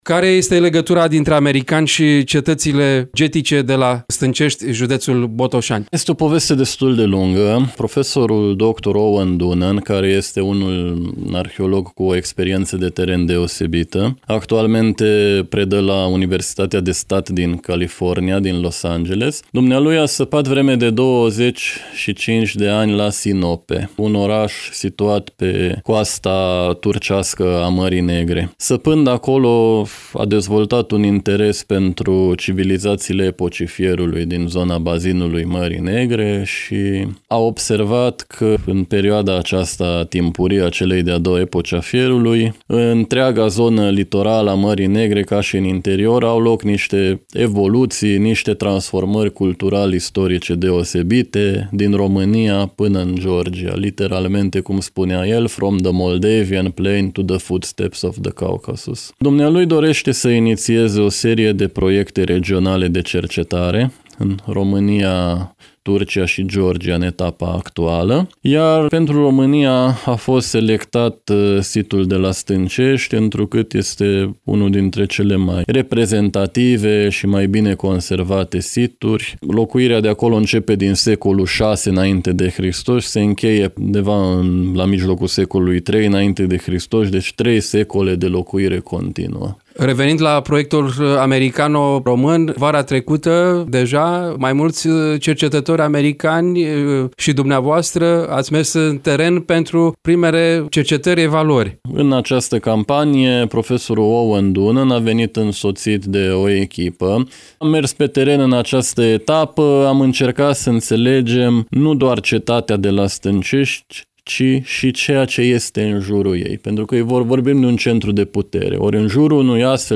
Varianta audio a interviului: Share pe Facebook Share pe Whatsapp Share pe X Etichete